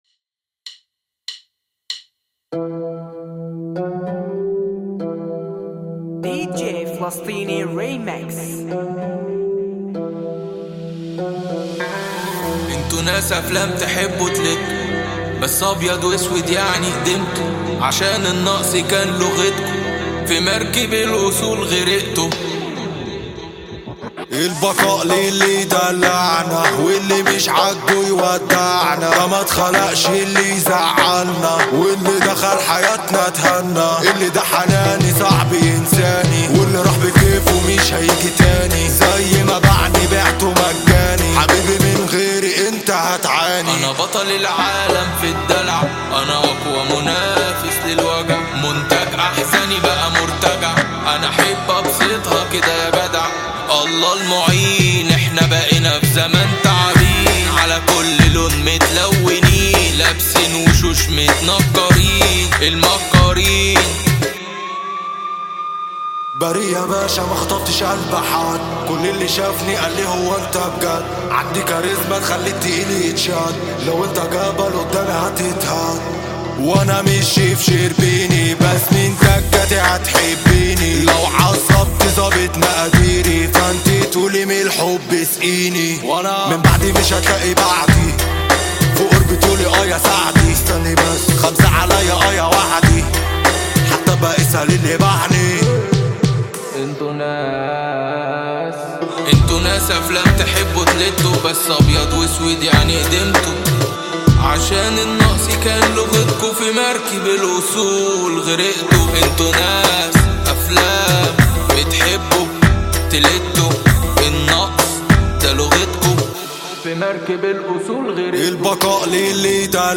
اغاني شعبي ومهرجانات